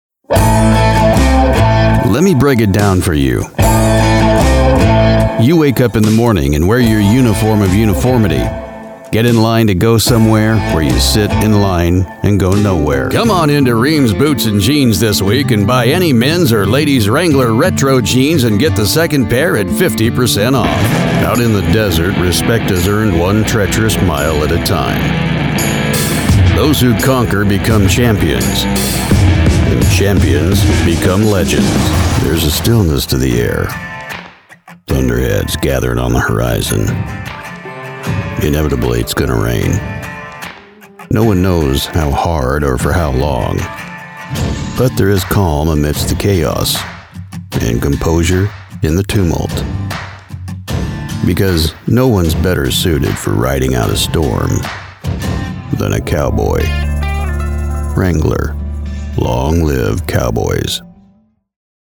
SPRACHAUFNAHMEN
Erweitern Sie Ihr 3D-Video mit einer professionellen sprachlichen Vertonung, die perfekt auf Ihre Bedürfnisse zugeschnitten ist.